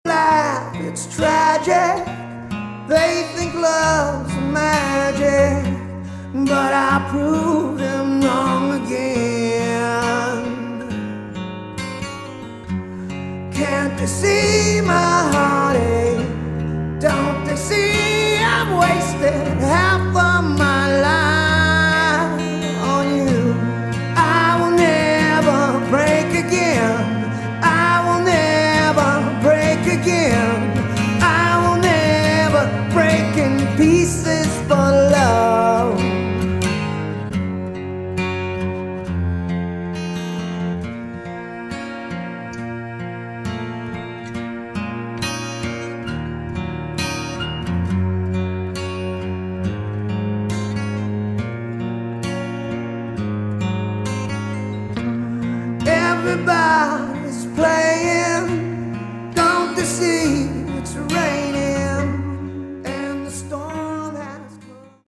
Category: Hard Rock
vocals, keyboard, guitar